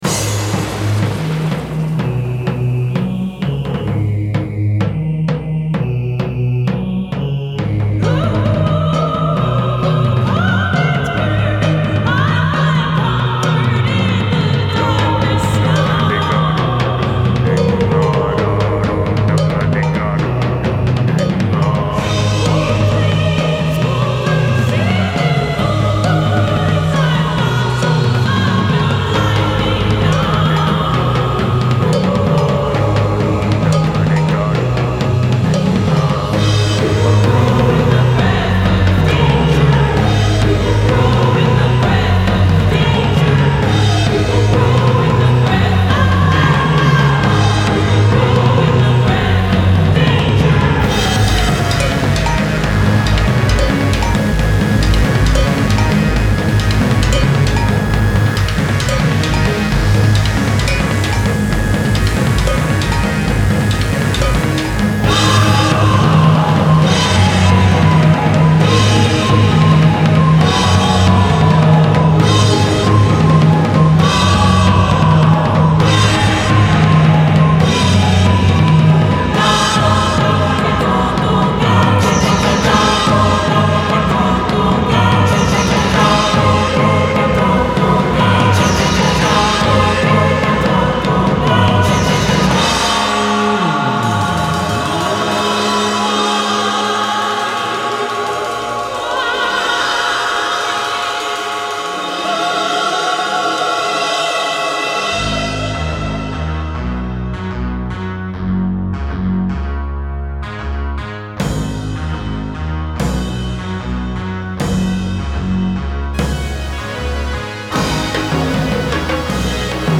They’re sound is ominous, spooky, arty and cool.